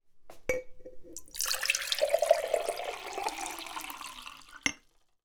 Wassergläser
Aus einem Glas wird Wasser in das andere gegossen das Ergebnis klingt wie im Beispiel.
wasserglaeser